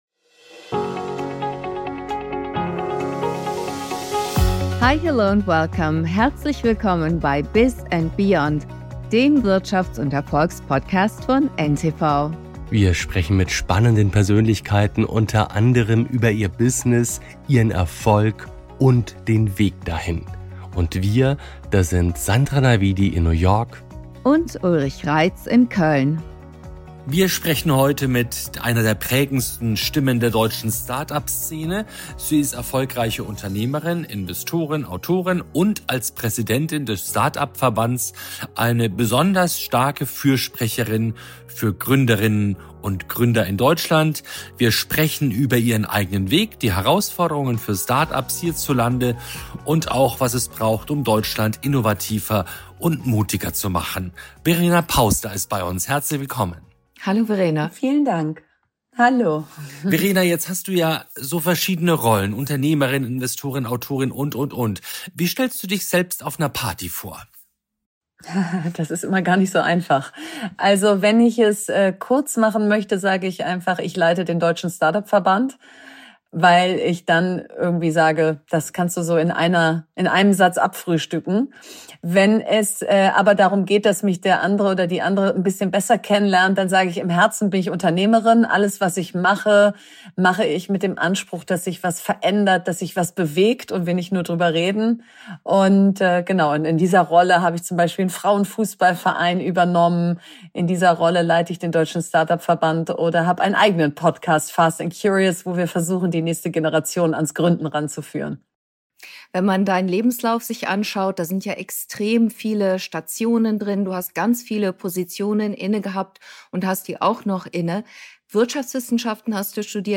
Ein inspirierendes Gespräch über Mut, Neugier und die unbändige Lust, etwas zu bewegen.